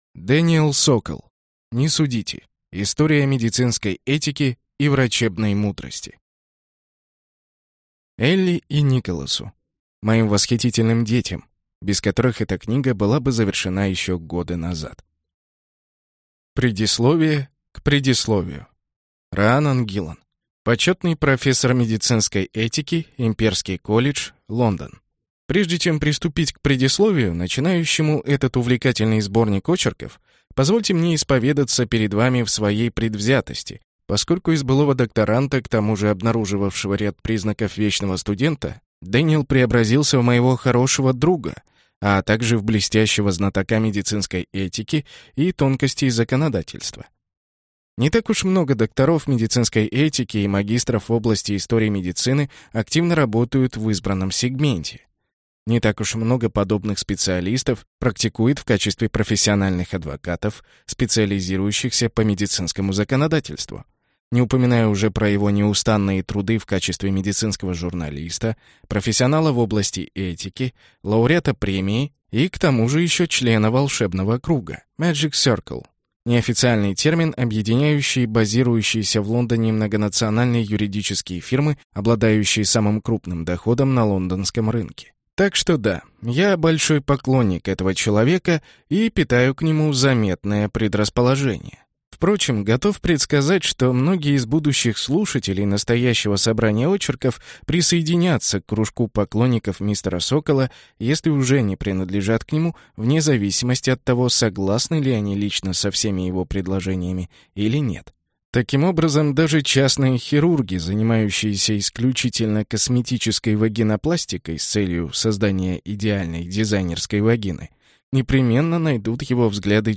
Аудиокнига Не судите. Истории о медицинской этике и врачебной мудрости | Библиотека аудиокниг